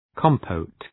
{‘kɒmpəʋt}